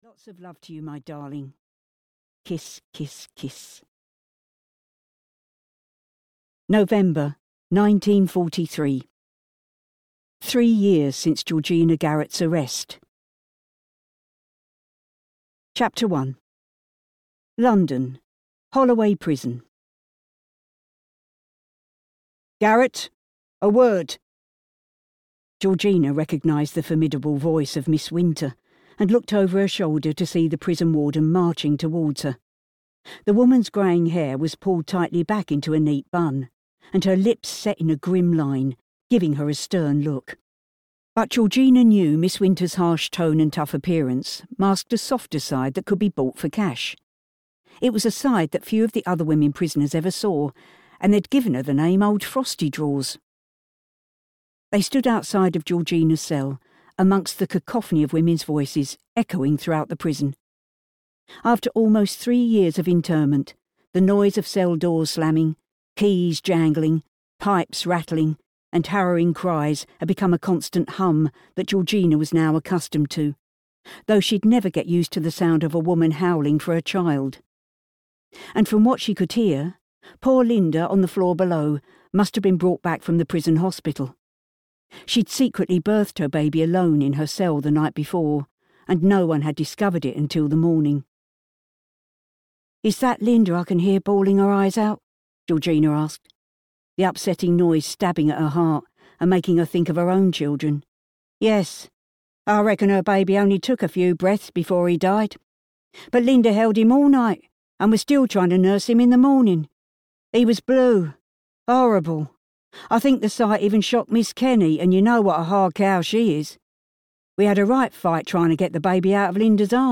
Siren (EN) audiokniha
Ukázka z knihy